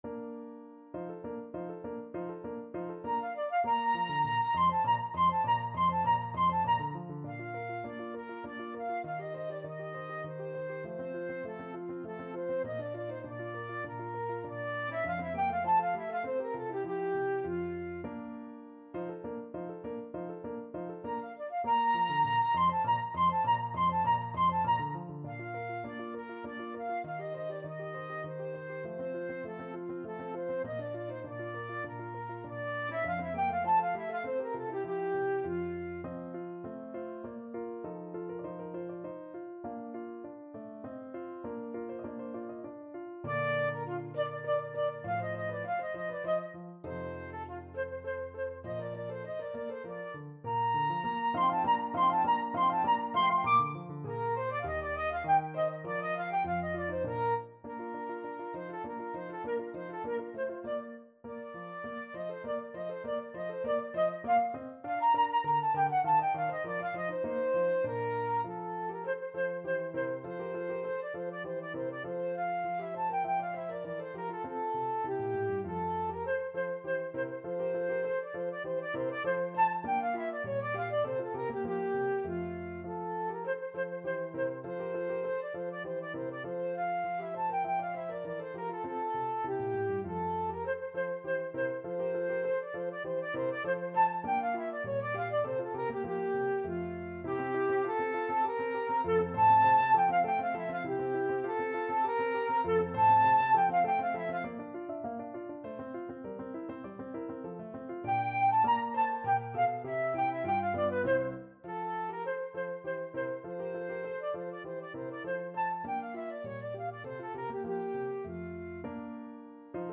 FlutePiano
3/4 (View more 3/4 Music)
Moderato
Flute  (View more Intermediate Flute Music)
Classical (View more Classical Flute Music)